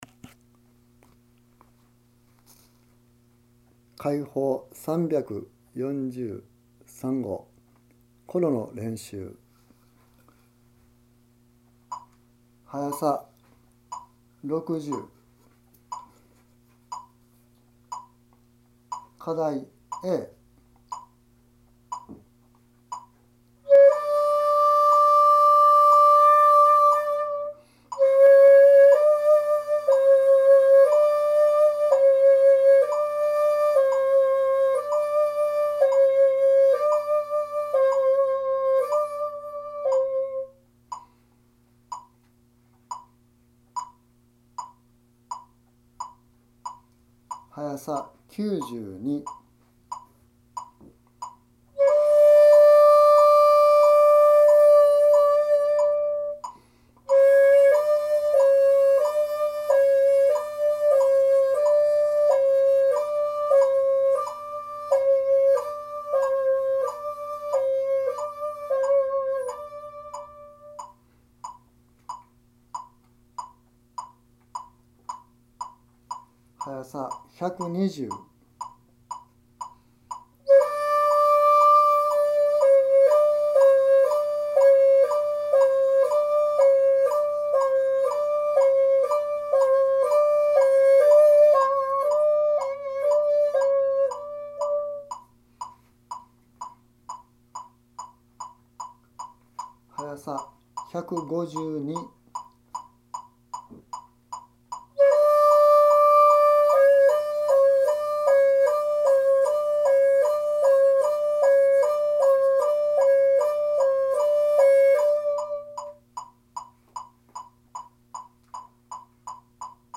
図の①②③②という動作を確実にきちんとできる速さで繰り返すのです。
何年後かに♩＝200ぐらいでできるようになったら今度は♩一拍に3つ音を入れましょう。
この段階になりますと「コロらしいコロ」が自ずとできるようになります。